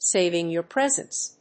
アクセントsáving your présence